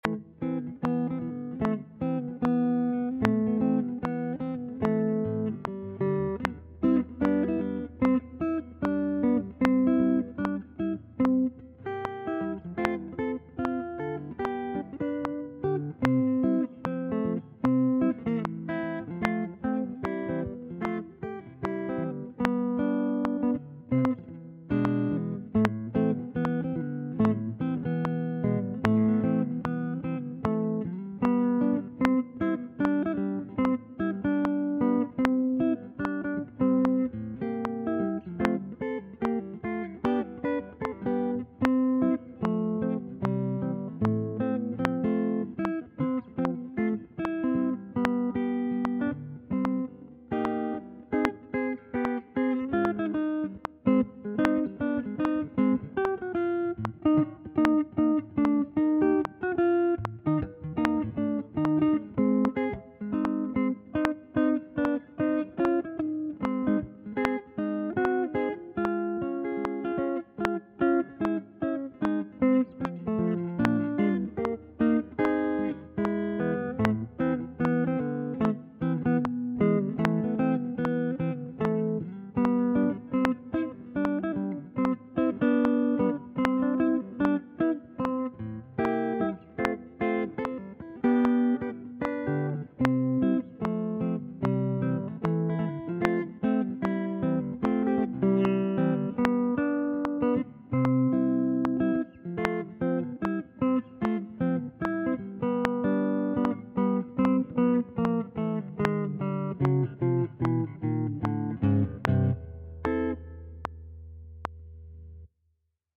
“Teddy Bear’s Picnic”, Bratton/Kenney in C at 75 BPM.
It remains popular in Ireland and the United Kingdom as a children’s song, having been recorded by numerous artists over the decades.
teddy-bears-picnic-C75.mp3